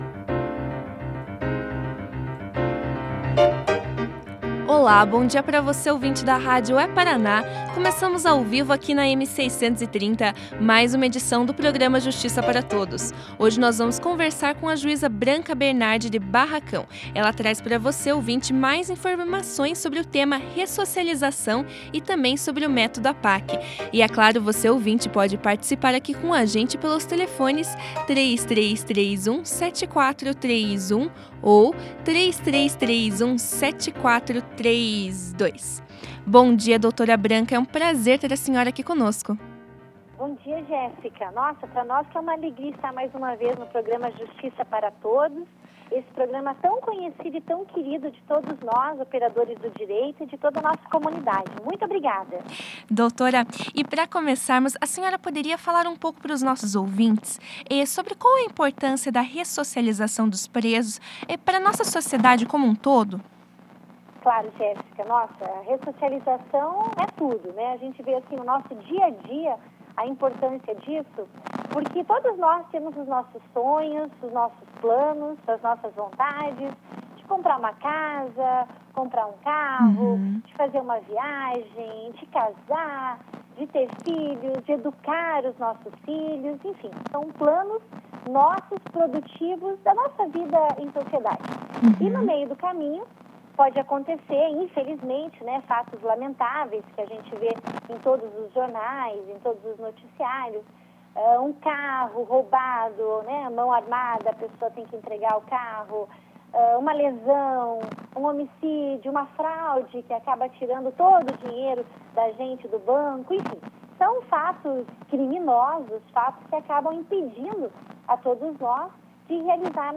No programa de rádio da AMAPAR dessa segunda-feira (18) a juíza Branca Bernardi falou aos ouvintes da rádio É-Paraná sobre ressocialização. A juíza começou a entrevista falando sobre a importância da ressocialização dos presos.
Ouça a entrevista da juíza Branca Bernardi sobre ressocialização na íntegra.